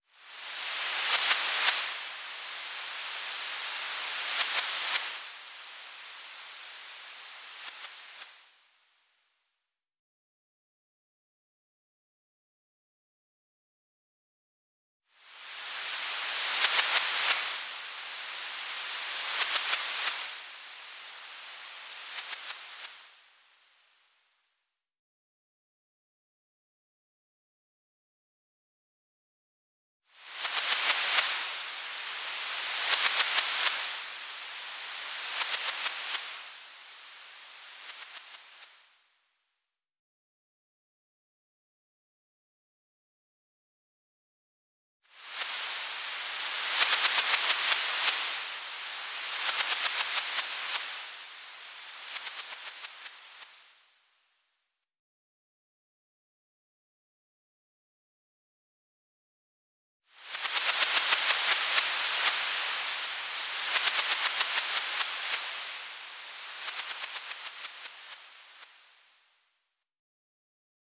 electroacoustic music